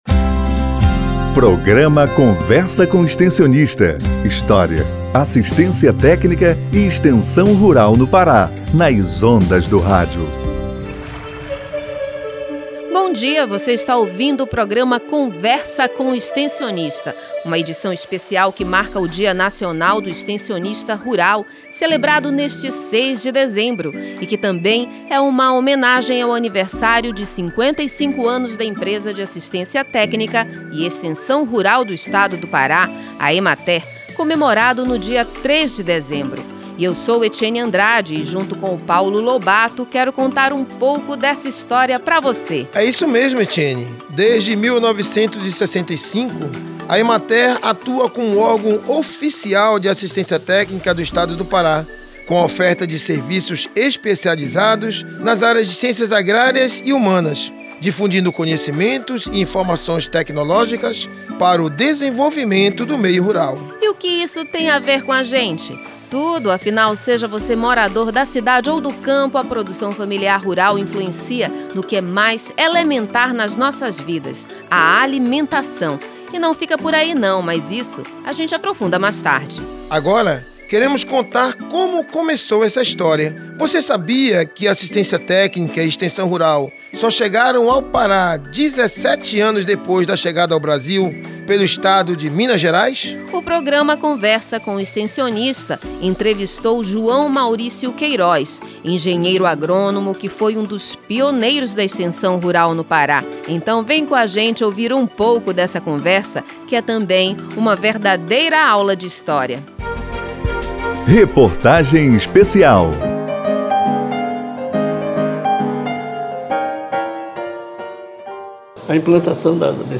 Programa de Rádio dos 55 anos da Emater pode ser acessado no site da empresa